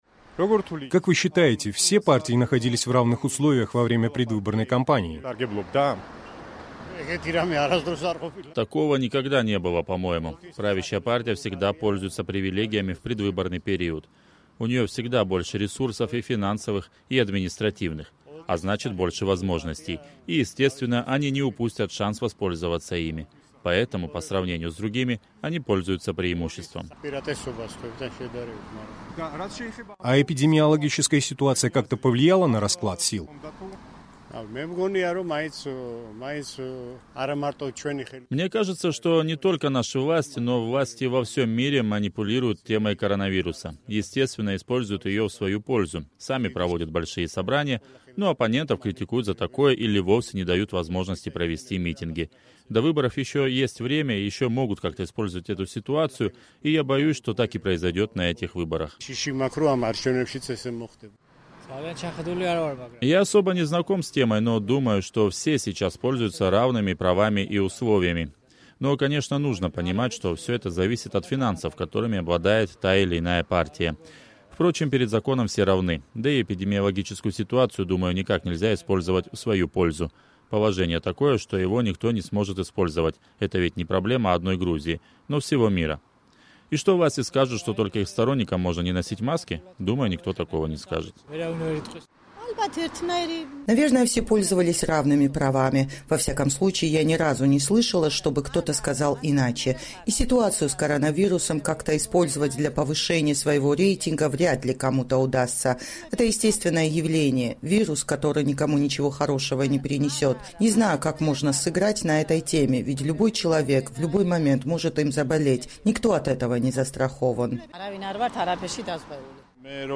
Как использовали партии нынешнюю ситуацию для укрепления своих политических позиций? В рубрике «Голоса» мы предложили тбилисцам подвести итоги предвыборной кампании времен коронавируса.